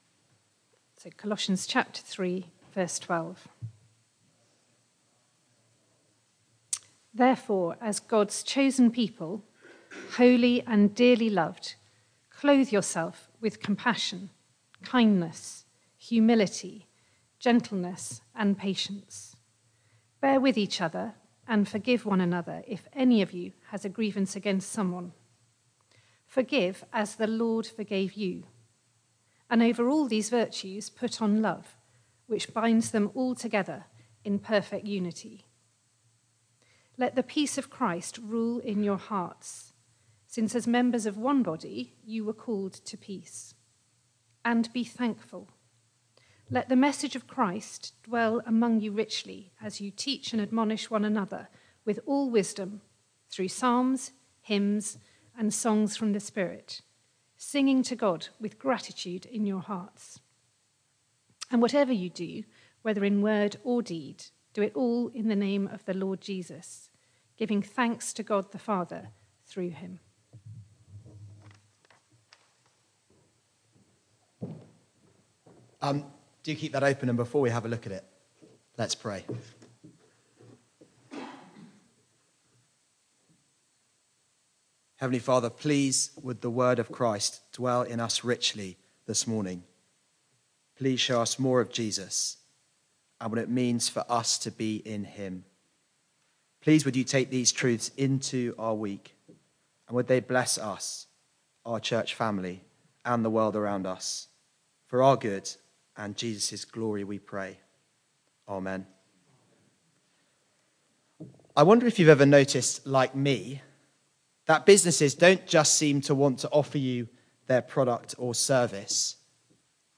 Media for Barkham Morning Service on Sun 09th Mar 2025 10:00
Theme: Sermon